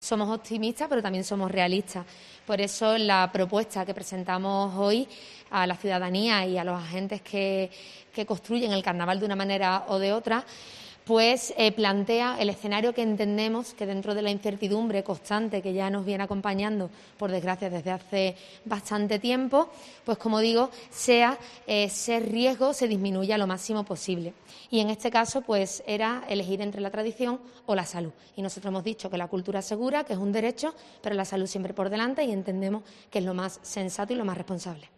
Lola Cazalilla, concejala de Fiestas del Ayuntamiento de Cádiz sobre la celebración del COAC 2022 en junio